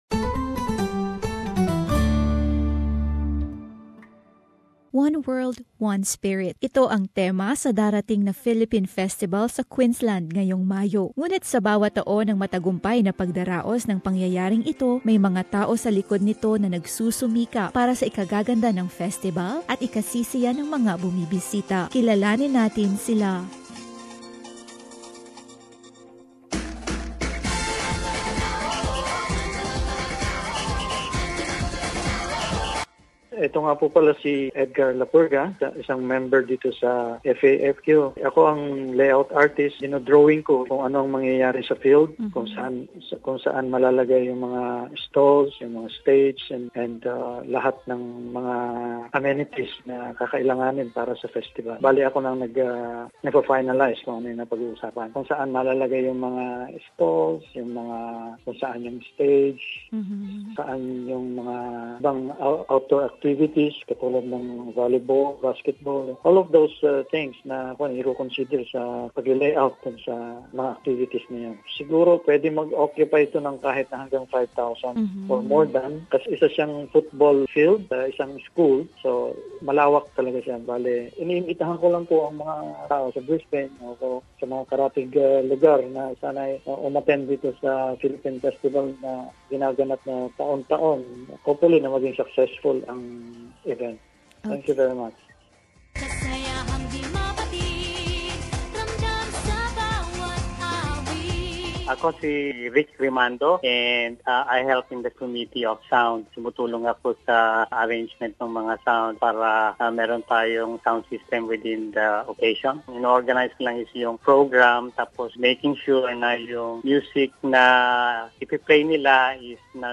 Ang mga nasa likod ng Pyestang Filipino sa Queensland ay kinapanayam upang malaman ang ilang aspeto ng okasyon.